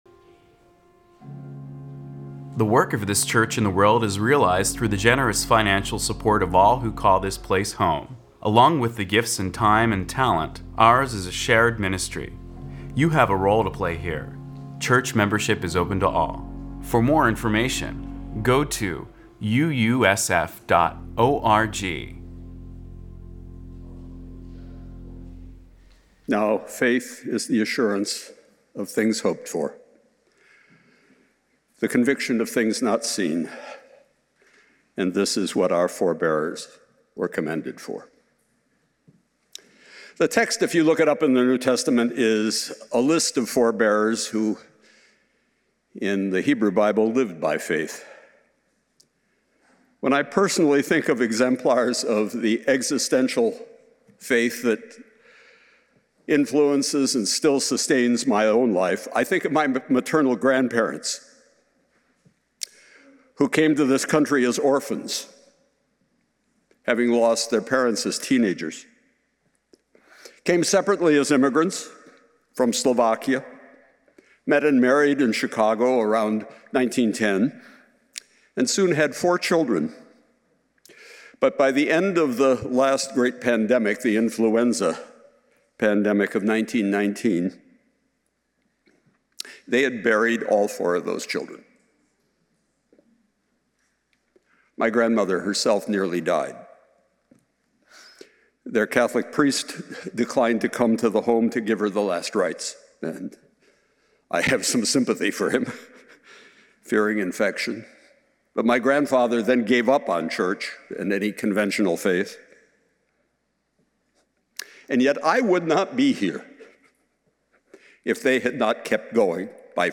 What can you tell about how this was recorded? The service will include a visit from one of the most colorful characters in our history: Emperor Norton!